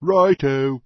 PeasantYes1.mp3